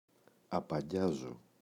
απαγκιάζω [apa’nɟazo] – ΔΠΗ